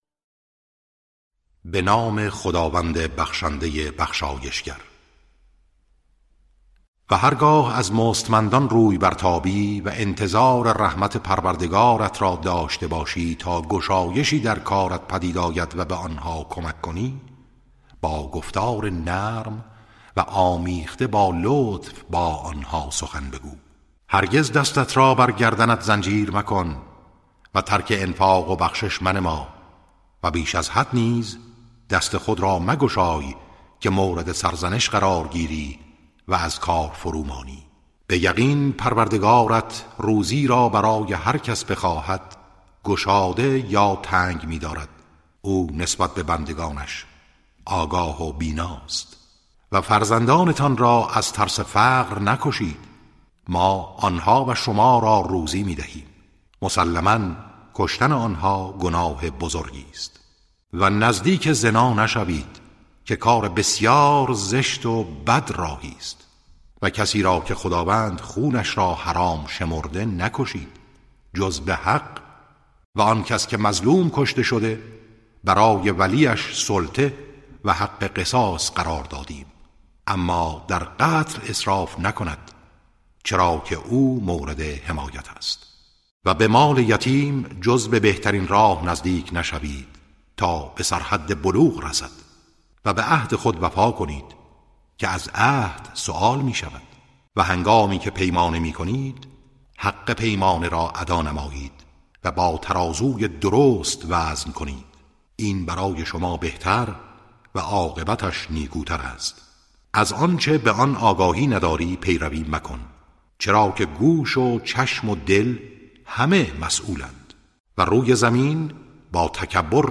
ترتیل صفحه ۲۸۵ از سوره اسراء(جزء پانزدهم)